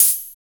TECH OHH.wav